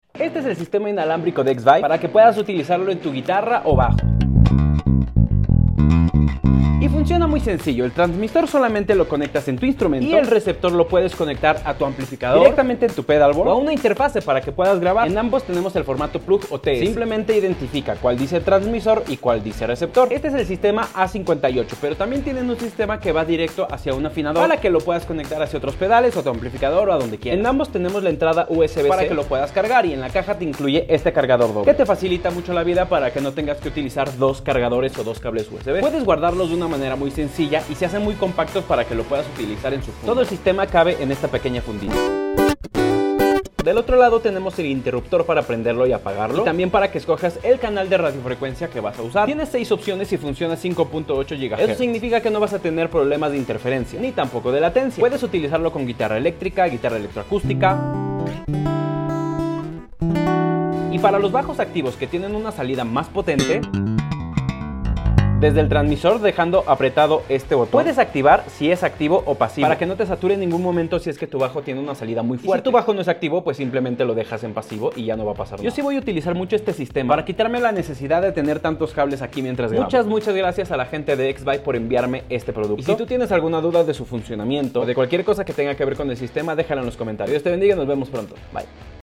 Pequeño, práctico y con un sonido que sorprende para su tamaño.